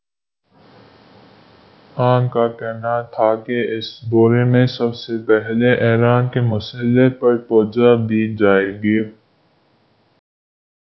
deepfake_detection_dataset_urdu / Spoofed_TTS /Speaker_17 /266.wav